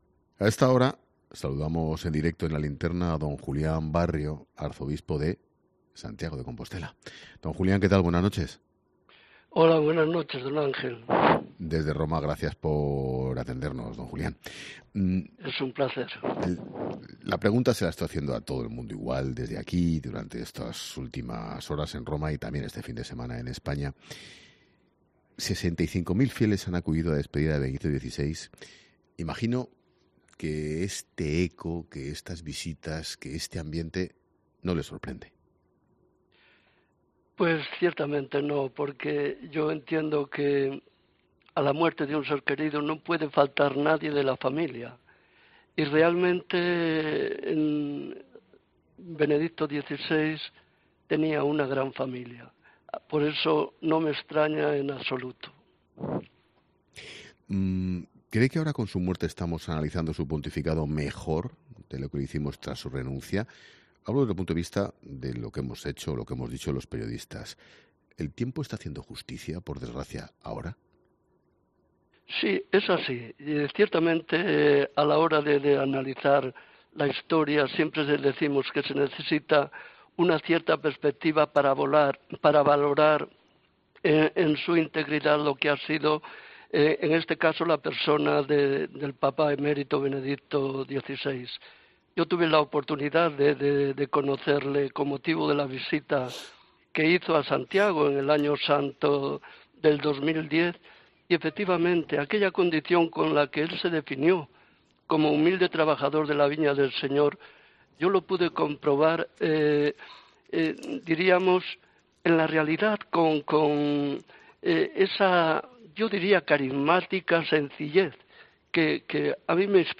Eso es precisamente lo que está sucediendo en estos días desde que conocimos su fallecimiento, tal y como ha afirmado el Arzobispo de Santiago, Julián Barrio, en 'La Linterna'.